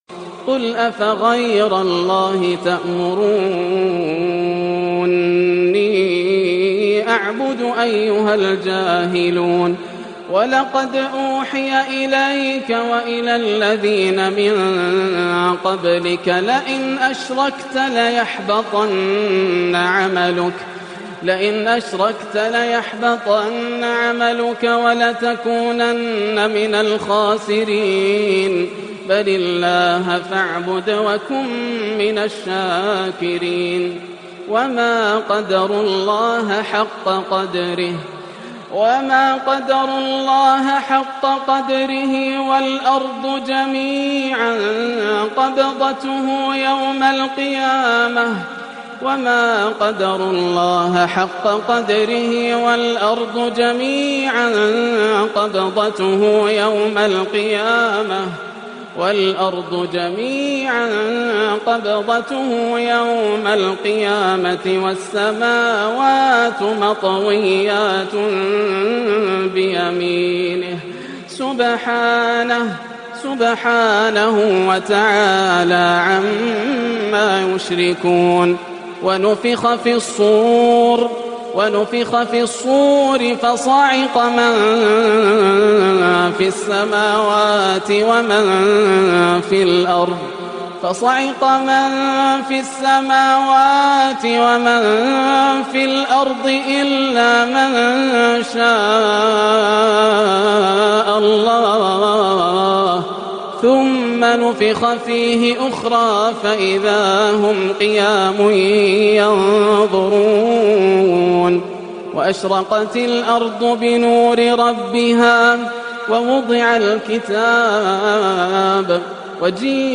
" وما قدروا الله حق قدره " الشيخ ياسر الدوسري - تلاوه خاشعه من سورة الزمر لعام 1435هـ